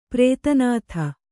♪ prēta nātha